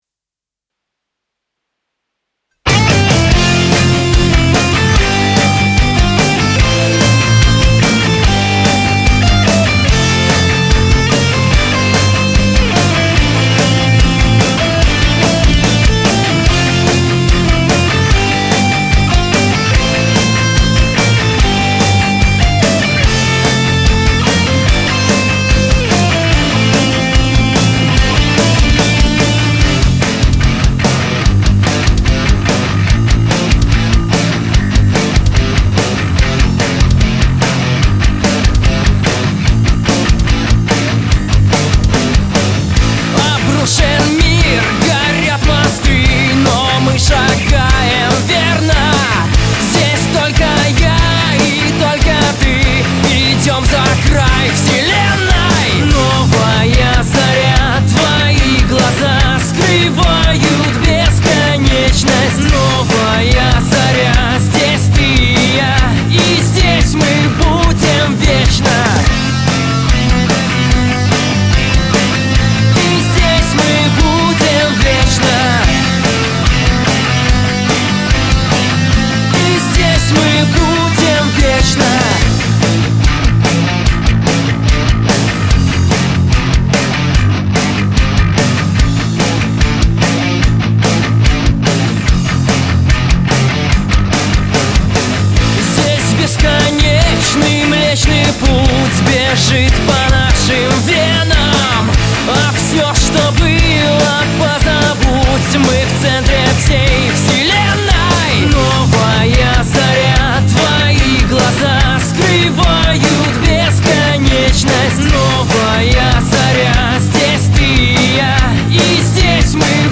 вокал
гитара
бас
ударные